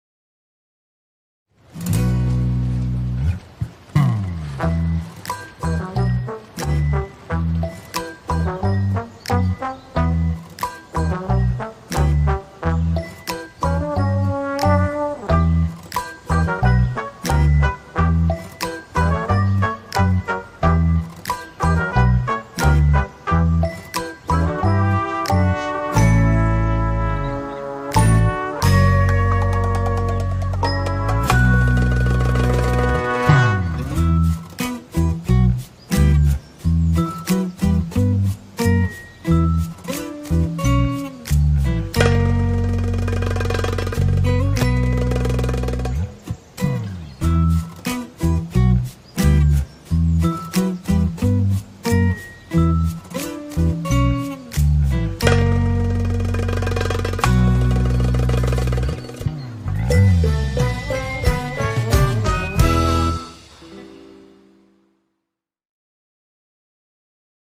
tema dizi müziği, neşeli eğlenceli enerjik fon müziği.